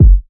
• Raw Kick Sound F# Key 284.wav
Royality free bass drum sample tuned to the F# note. Loudest frequency: 78Hz
raw-kick-sound-f-sharp-key-284-Fk1.wav